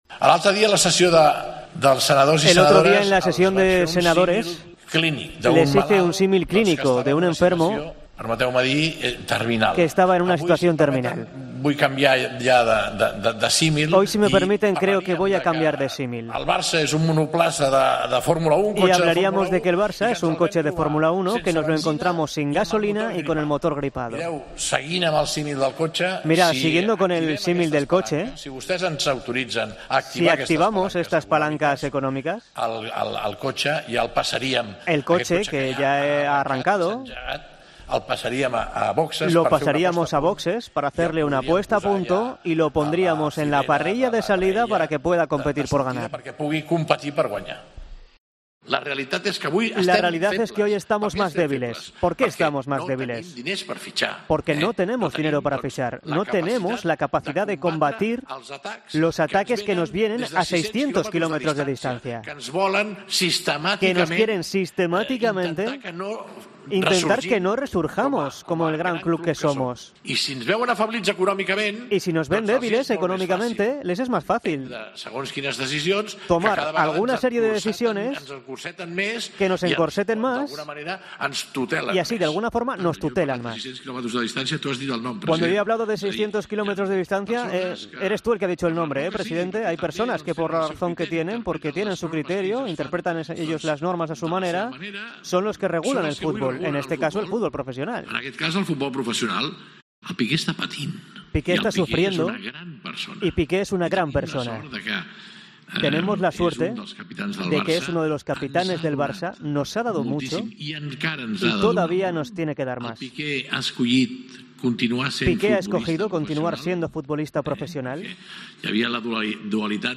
Joan Laporta, en la Asamblea de socios compromisarios del Barcelona